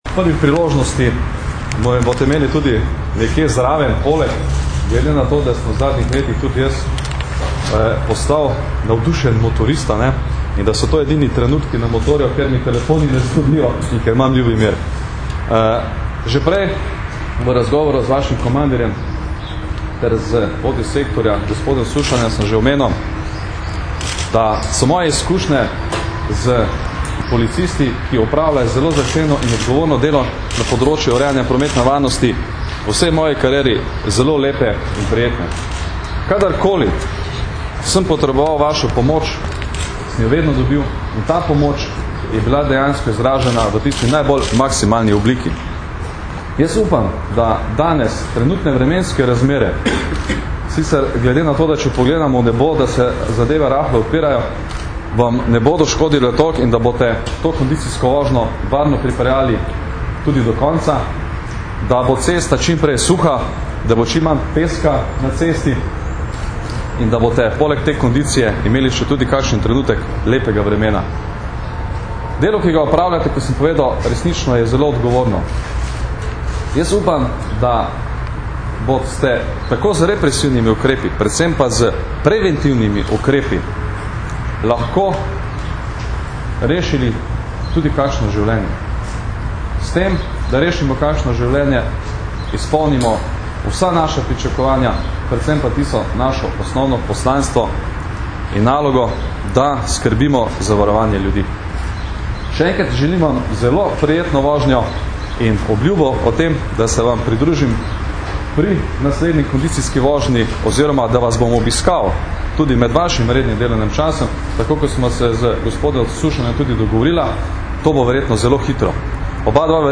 Zvočni posnetek izjave Janka Gorška, v. d. generalnega direktorja policije (mp3)